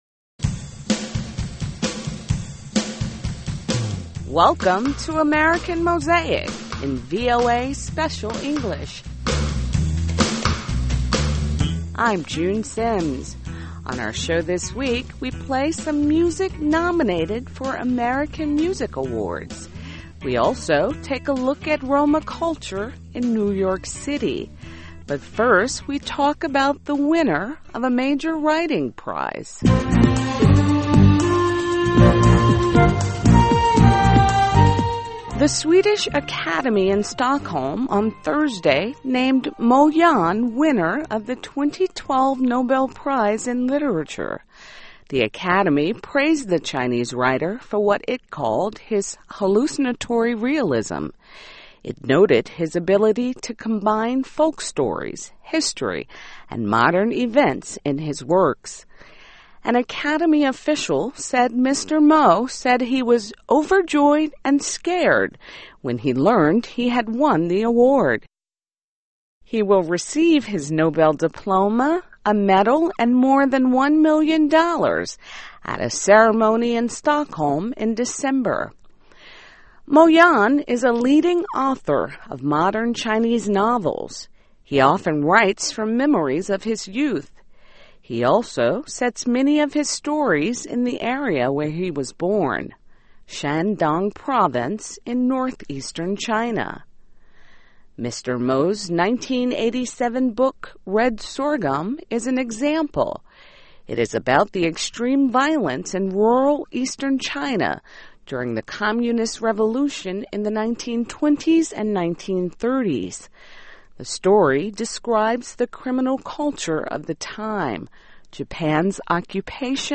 On our show this week, we play some music nominated for American Music Awards. We also take a look at Roma culture in New York City.